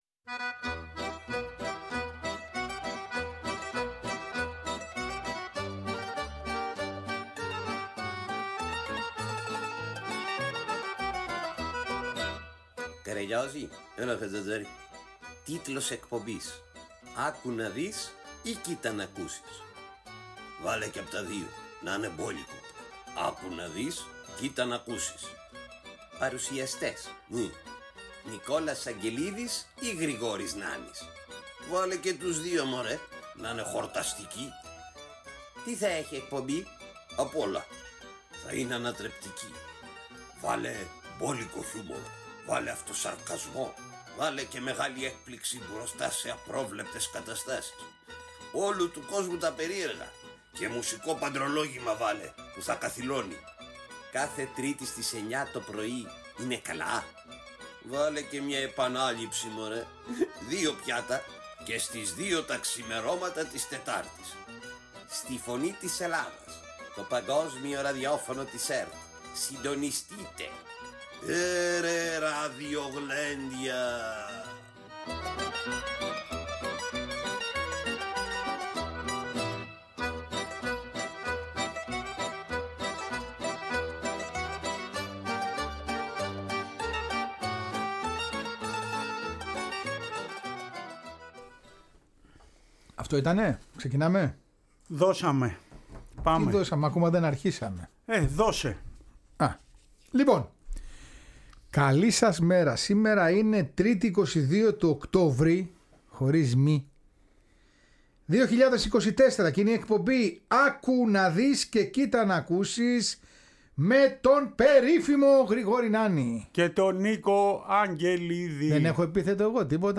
Τέλος μαθαίνουμε την ιστορία της Αναγέννησης από τον Έμπωνα Ρόδου και ακούμε τον εκπληκτικό ύμνο της ομάδας που αγωνίζεται στην ΕΠΣ Δωδεκανήσου.